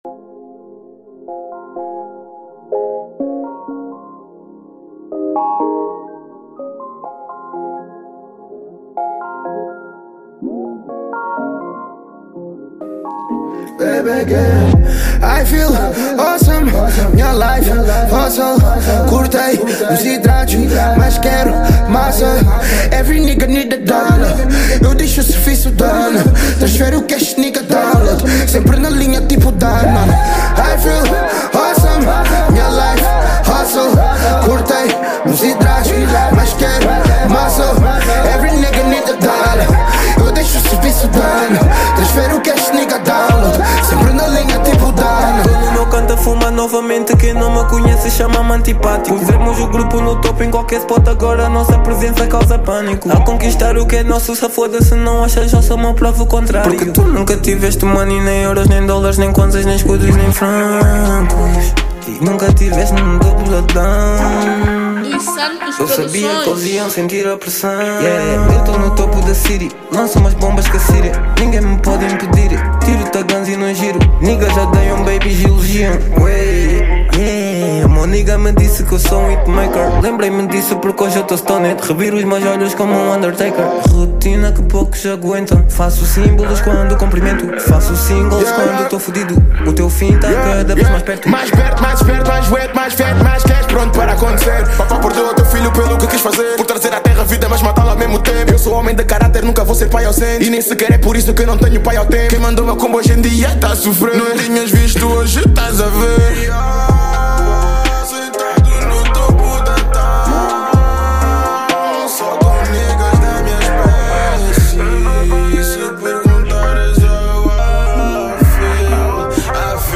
Hip-Hop / Rap